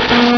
Cri d'Ymphect dans Pokémon Rubis et Saphir.